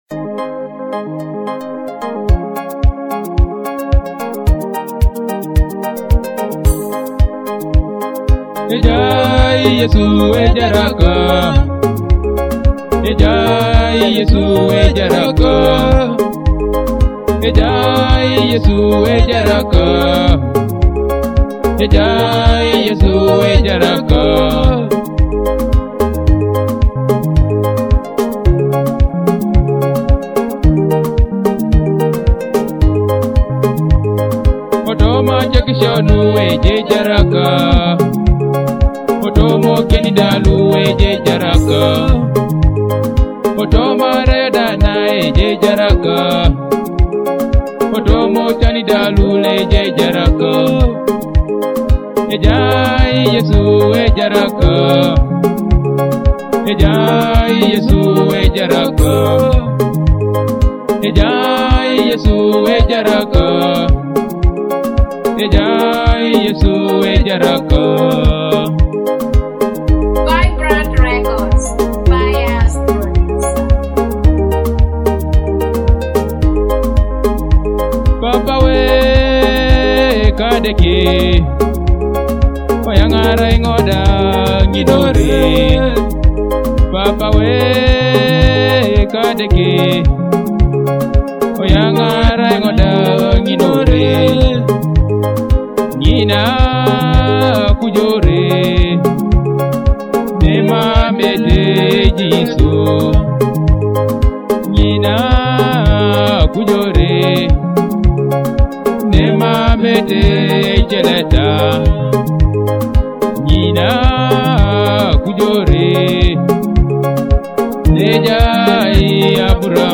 feel the uplifting spirit of this must-have anthem